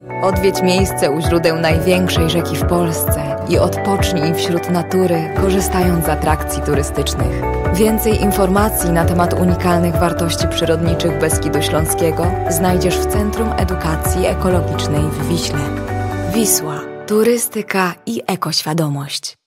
Female 20-30 lat
Warm, deep, expressive voice.
Spot reklamowy